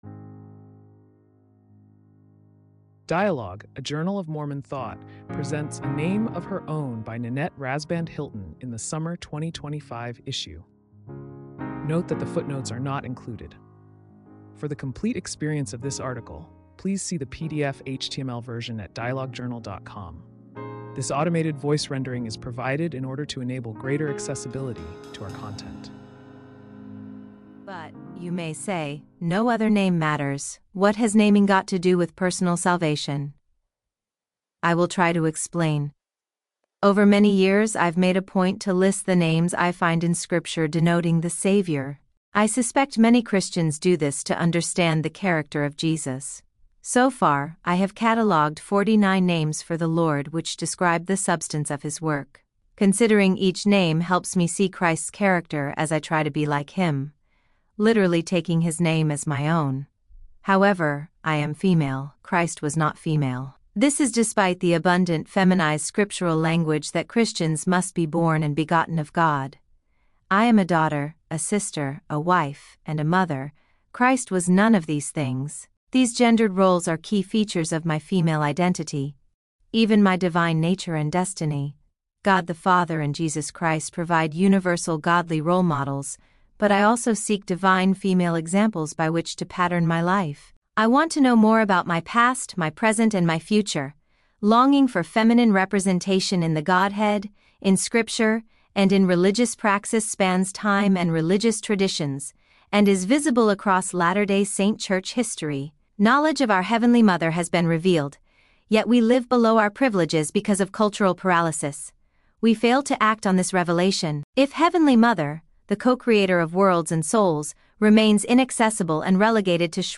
This automated voice rendering is provided in order to enable greater accessibility to…